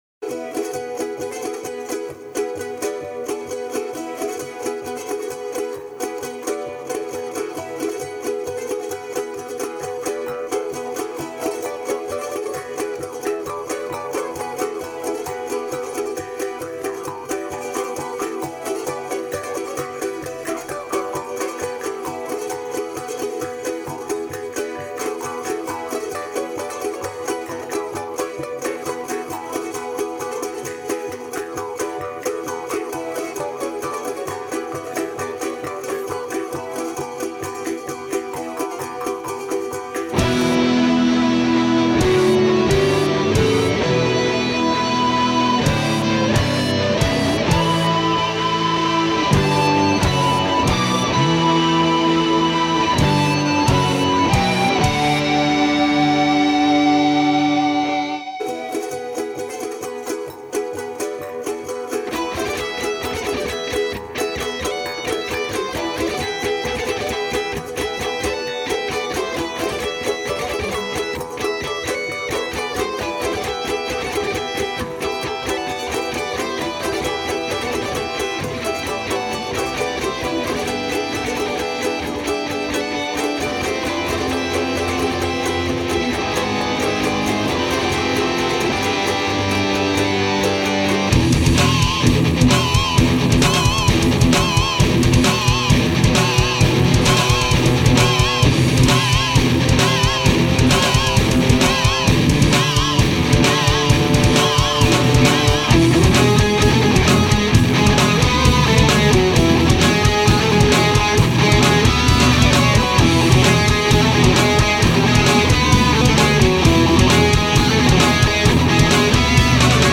są również mandoliny, git akustyczna, flet oraz drumla.